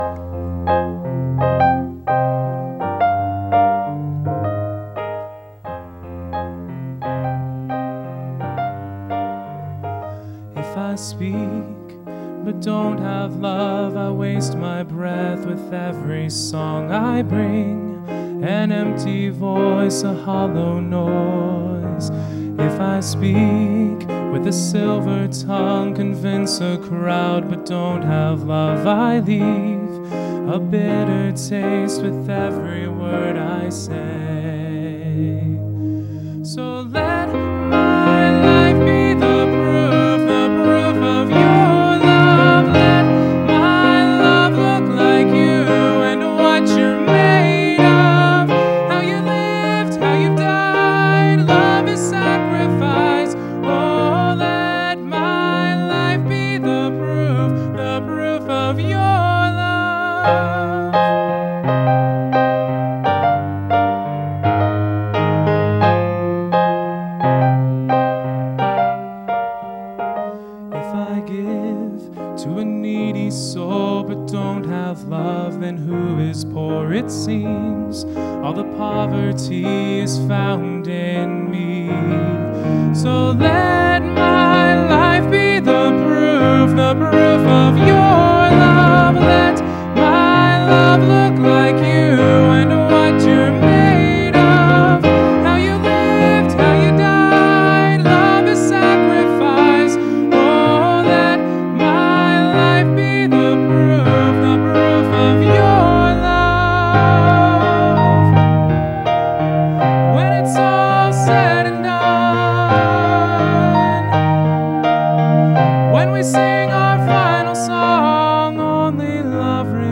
Special at Faith Baptist